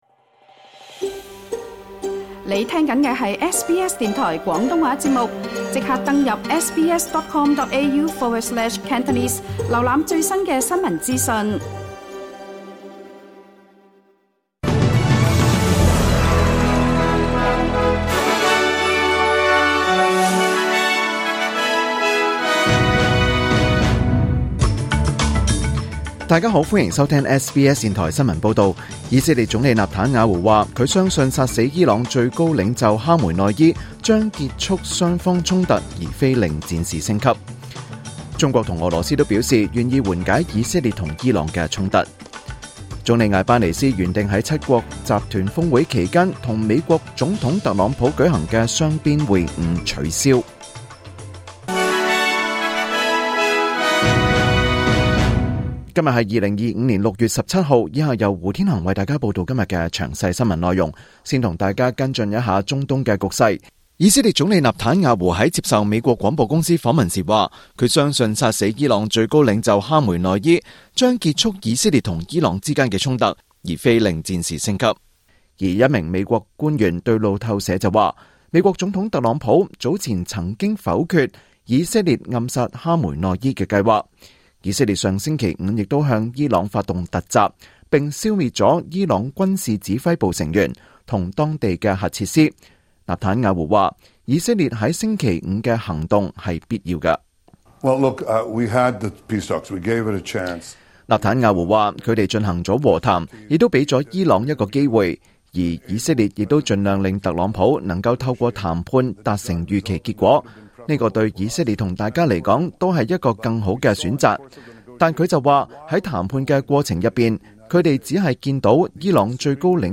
2025年6月17日SBS廣東話節目詳盡早晨新聞報道。